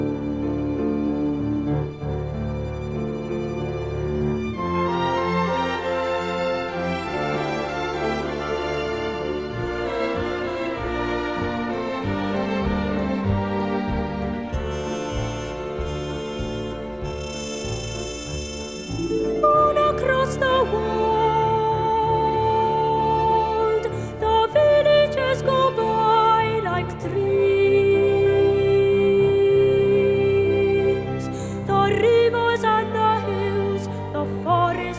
# Children's Music